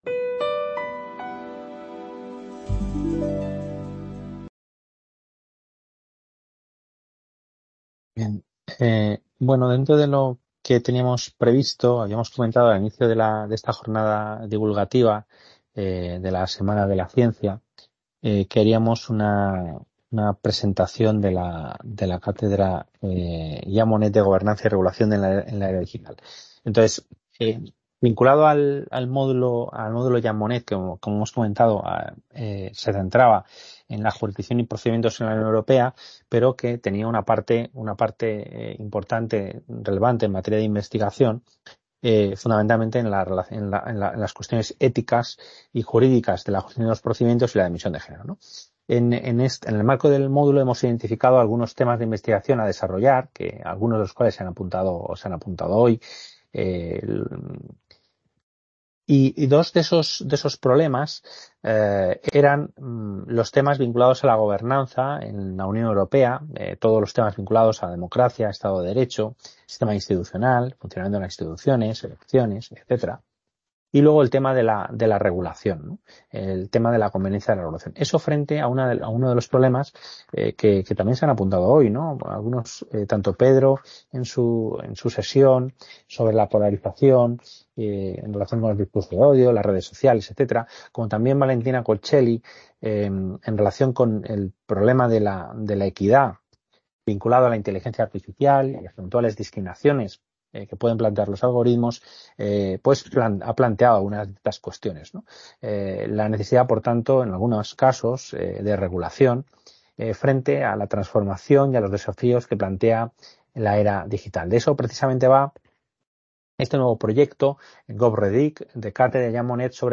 Sesión de la Semana de la Ciencia de Madrid 2023.
Video Clase